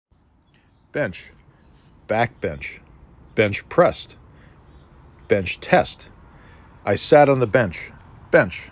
5 Letters, 1 Syllable
4 Phonemes
b e n C
C = lunch, watch